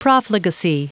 Prononciation du mot : profligacy